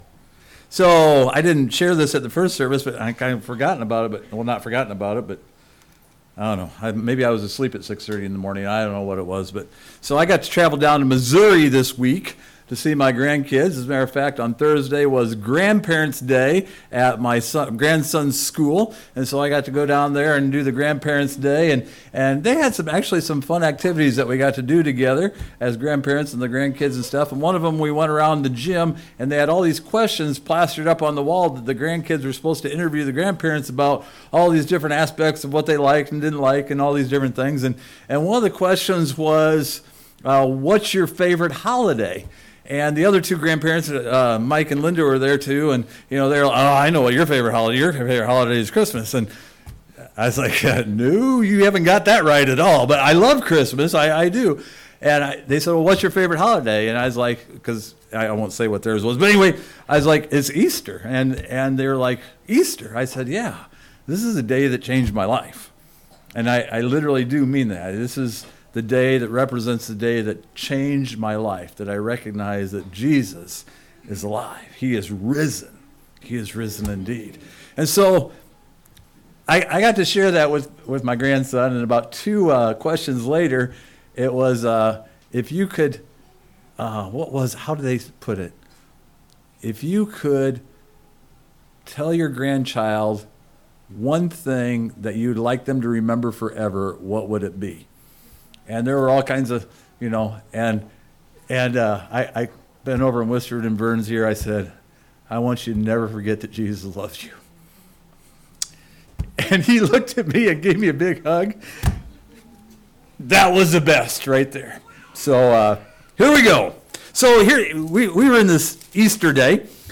Easter Service 2023
Service Type: Sunday Morning As we look into Resurrection Morning from the 4 gospels we begin to wonder about the day to change all days and our own mindset between focusing on the first day of the week verses the 3rd day that was prophesied.